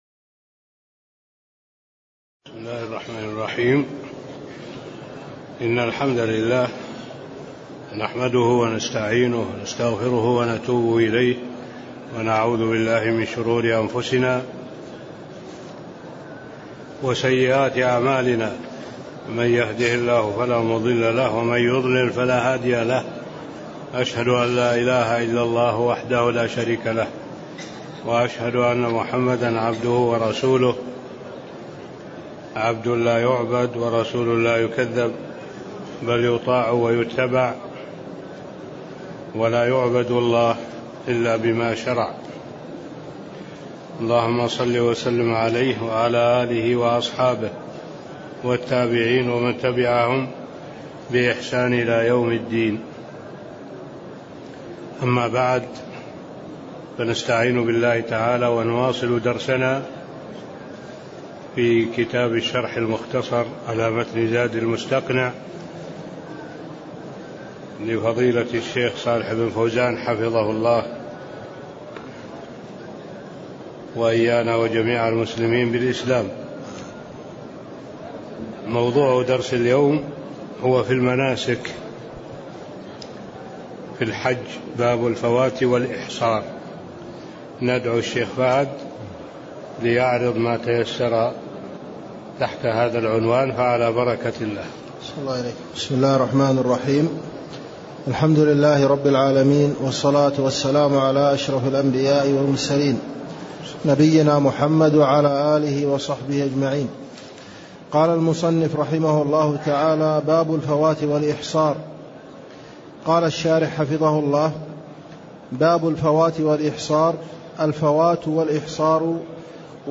تاريخ النشر ٩ ذو القعدة ١٤٣٤ هـ المكان: المسجد النبوي الشيخ: معالي الشيخ الدكتور صالح بن عبد الله العبود معالي الشيخ الدكتور صالح بن عبد الله العبود باب الفوات و الإحصار (07)